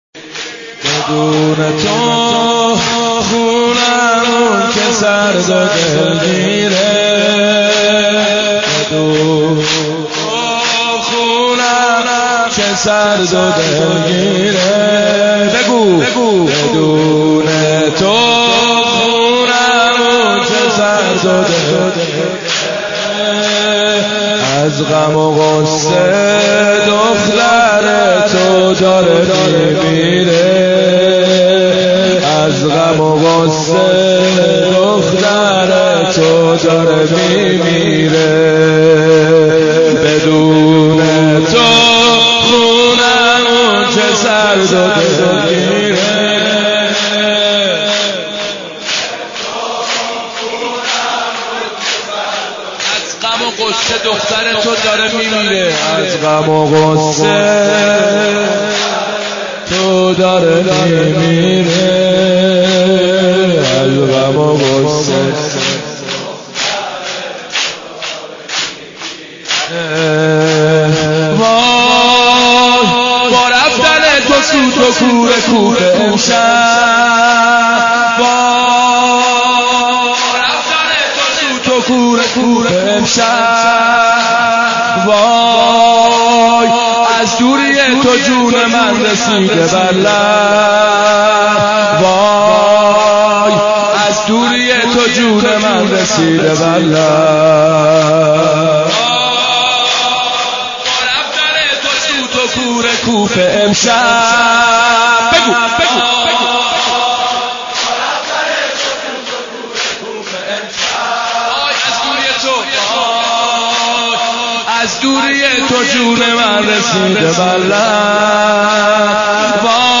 رمضان 89 - سینه زنی 3